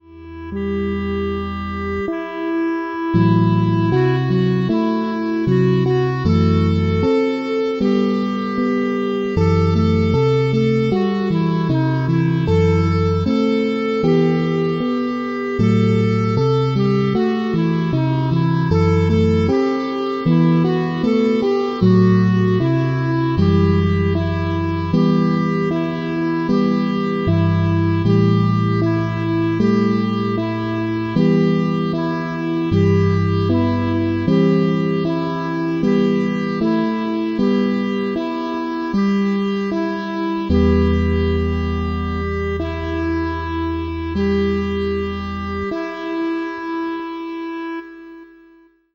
E minor